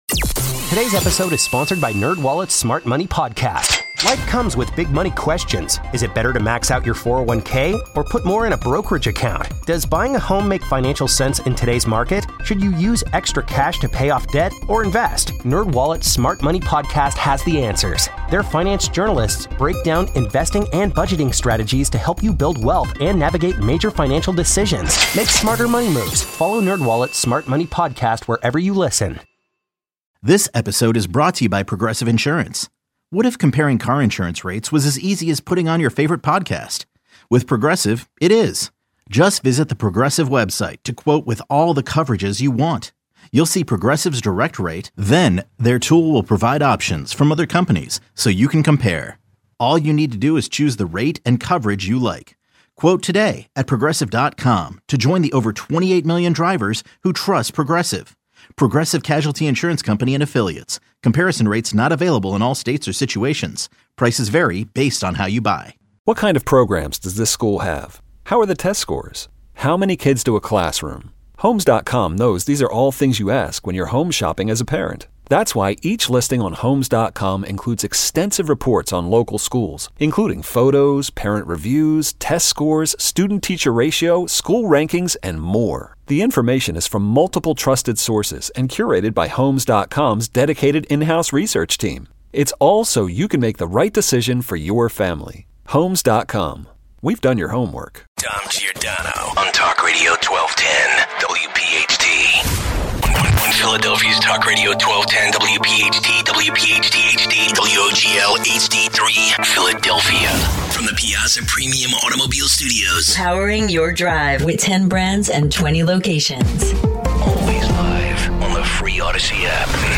Are standards for police cadets sexist or racist? 130 - Charleroi Mayor Gregg Doerfler and Councilman Larry Celashi join us today to get updates from their small PA town that was overrun with Haitian immigrants. With a businessman pleading guilty to labor crimes, will this bring closure to the town?